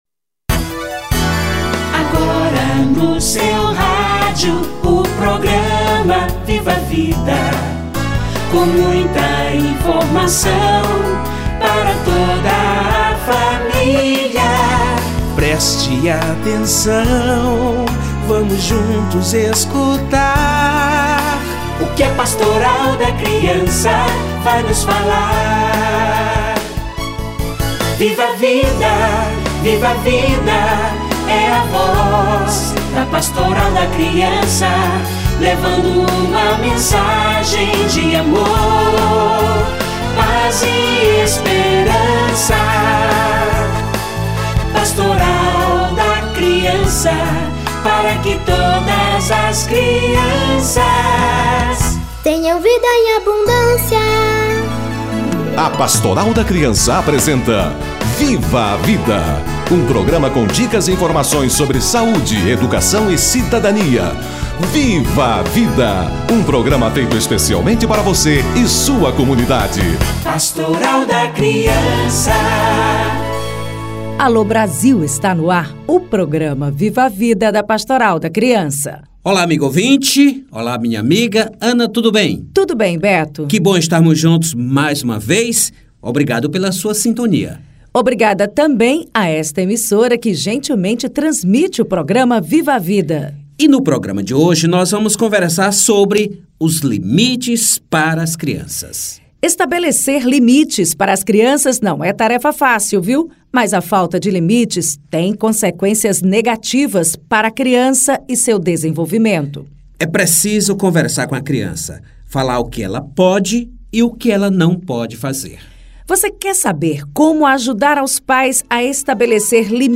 Limites nas crianças - Entrevista